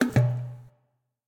level_up.ogg